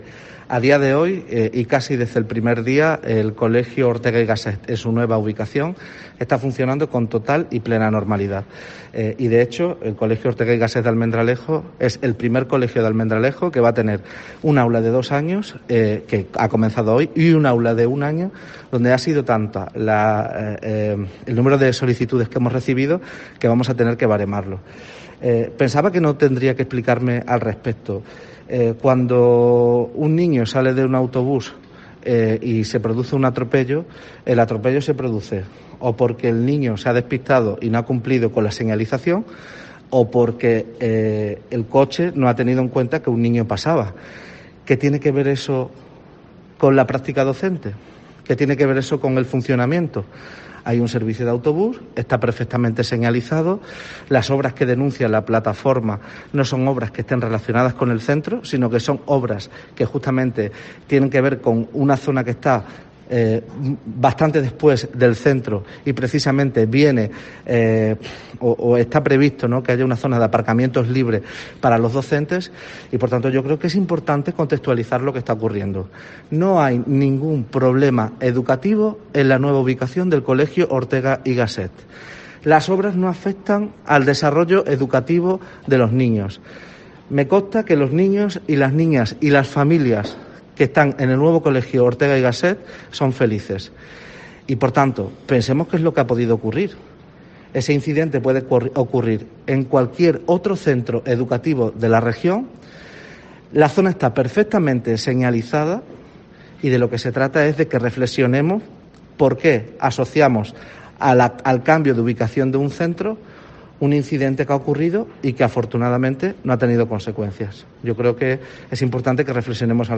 COPE le ha preguntado al secretario general de Educación, Fran Amaya, por las denuncias que lleva haciendo la plataforma ‘No al cierre del Ortega’ desde que se comenzaran a impartir las clases en el nuevo colegio, el pasado 10 de enero.